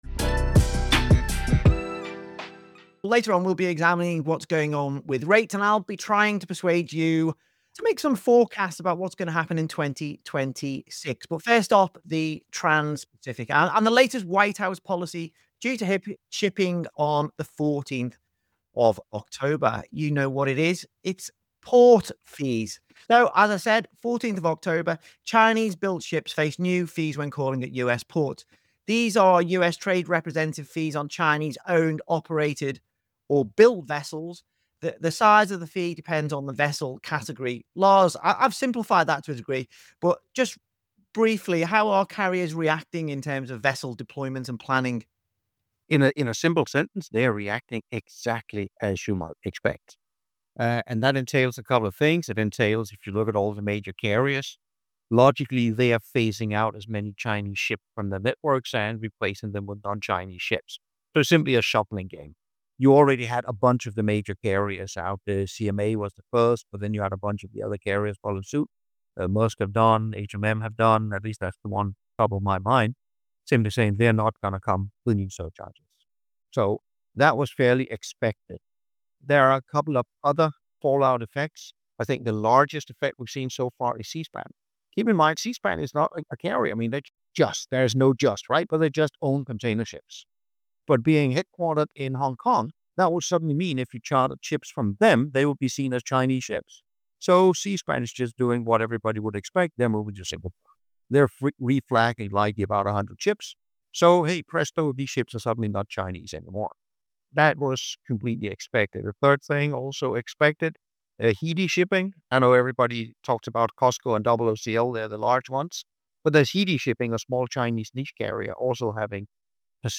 This extended clip is from The Big Interview – Freight Buyers Club EP54, produced with the support of Dimerco Express Group. https